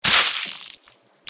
splat.ogg